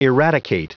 Prononciation du mot eradicate en anglais (fichier audio)
Prononciation du mot : eradicate